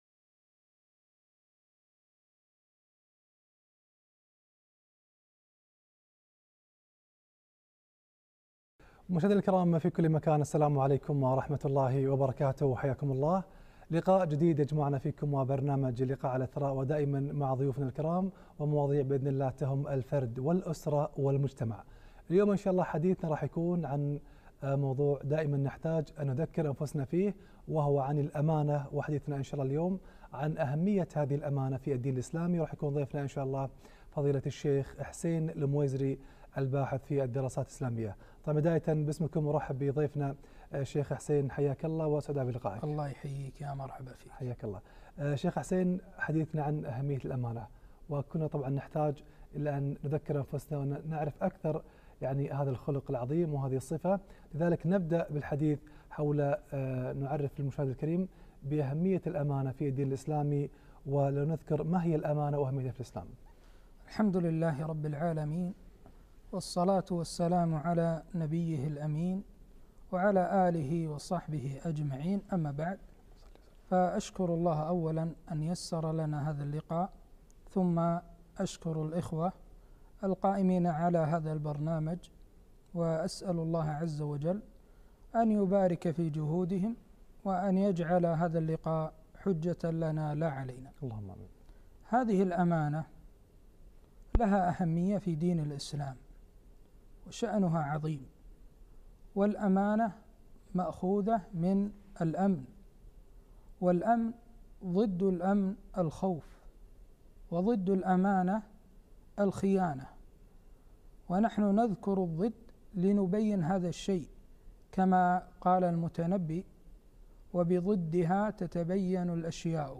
أهمية الأمانة - لقاء إذاعي